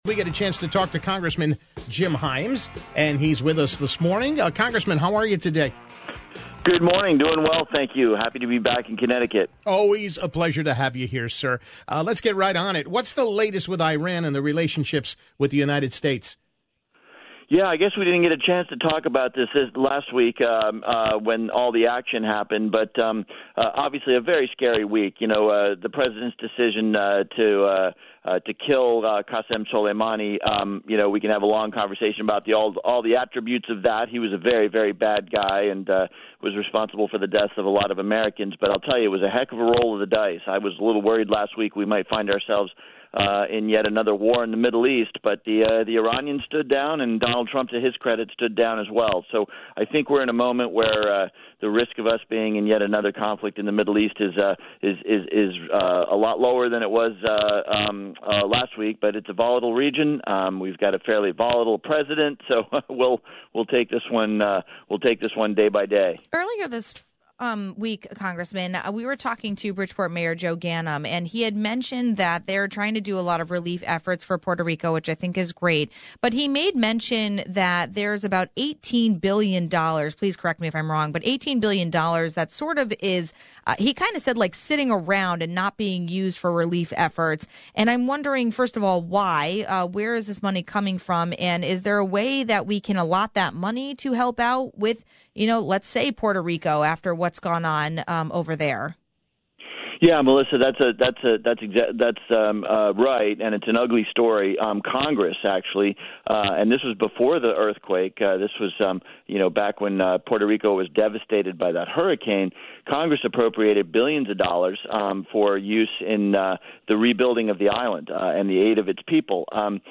Congressman Jim Himes talked about a number of topics including the relationship now between Iran and the US. But he also touched on a remark made by Bridgeport Mayor Joe Ganim regarding 18 billion dollars not being used to help Puerto Rico following the earthquakes.